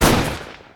bullet_1.wav